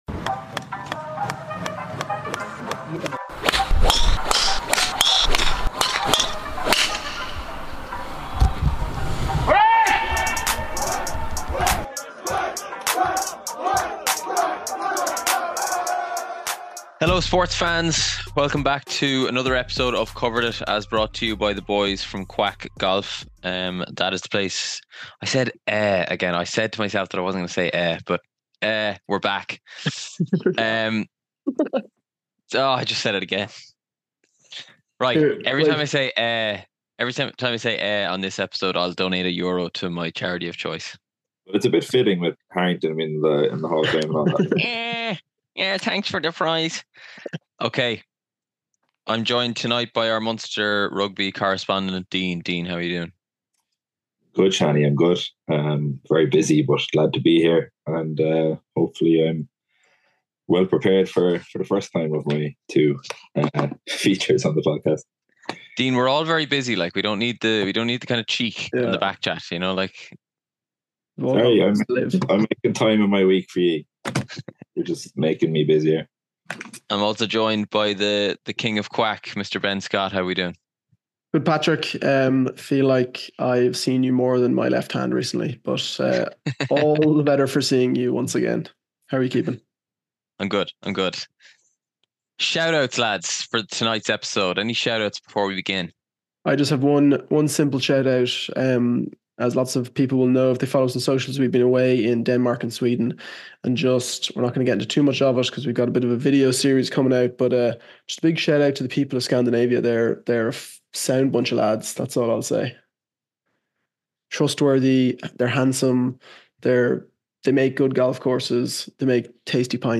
Stick around for a light-hearted chat between friends with some excellent facts for your next dinner party.